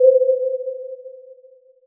Aquí se presentan los siguientes sonidos generados mediante goldwave que ilustran los fenómenos del umbral no lineal de audición, los efectos de enmascaramiento de una señal o la percepción logarítmica de la potencia de los sonidos.
Sonar: Vemos como este sonido típico de las pelícuals de submarinos emitido por los sonares no es mas que la combinación de tonos de la misma potencia muy proximos en frecuencia y multiplicados claro por una exponencial que hace que el sonido desaparezca poco a poco. sin(2*pi*t*f)-sin(2*pi*t*(f*1.031))+sin(2*pi*t*(f*1.047))-sin(2*pi*t*(f*1.057)))*exp(-t*3)/4
sonar.wav